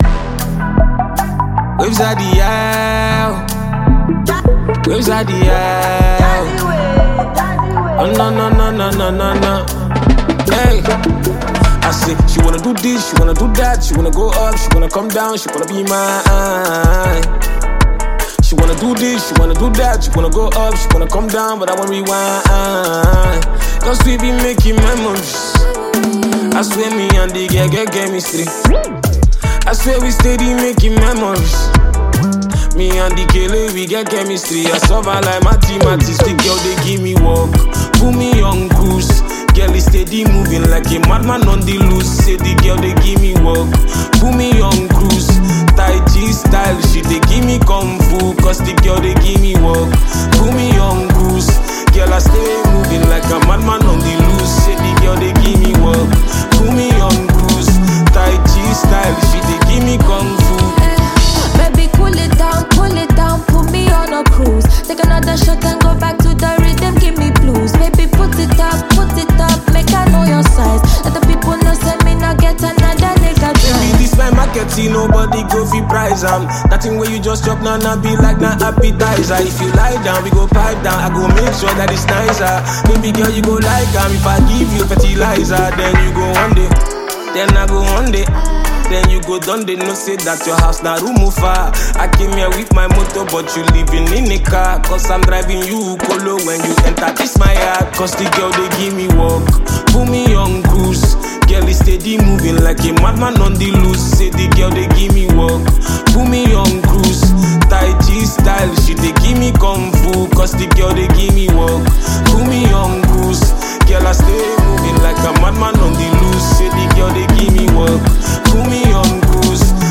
Prominent Nigerian Singer, and Songwriter